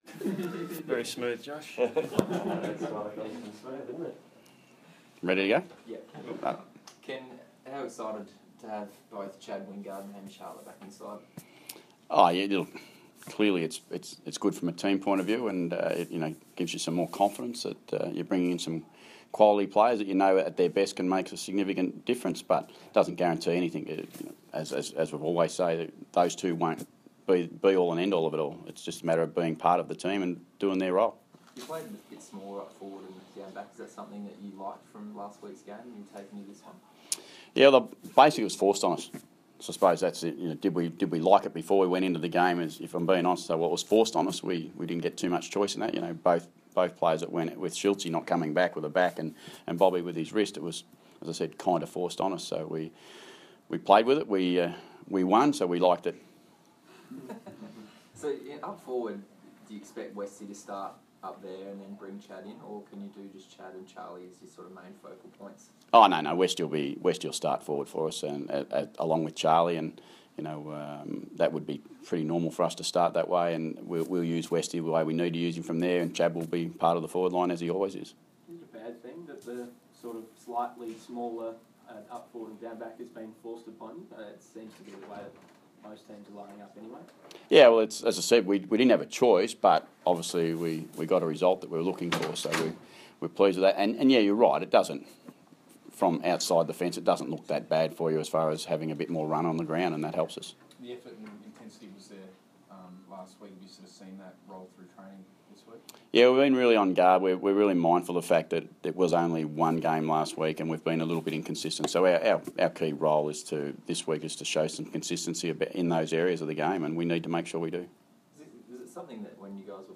Ken Hinkley Press Conference - Friday 15 April 2016
Ken Hinkley addresses the media ahead of Sunday's clash with GWS